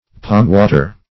Search Result for " pomewater" : The Collaborative International Dictionary of English v.0.48: Pomewater \Pome"wa`ter\, n. A kind of sweet, juicy apple.
pomewater.mp3